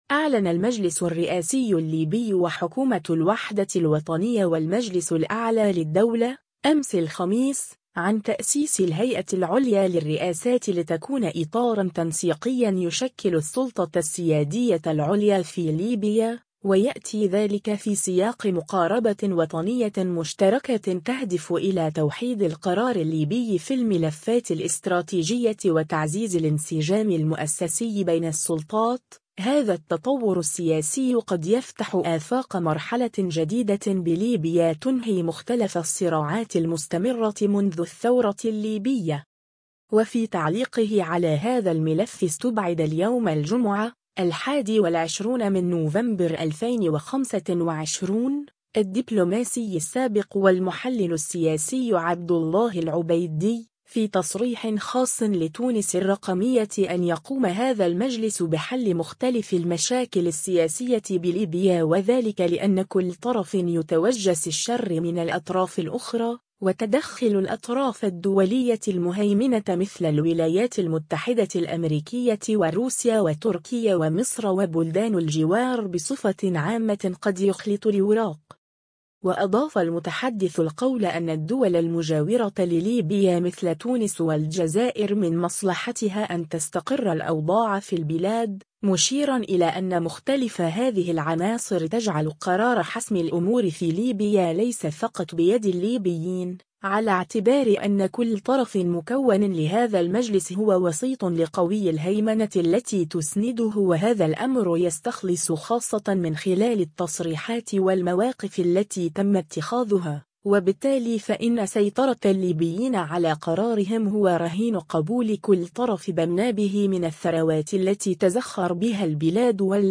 تصريح خاص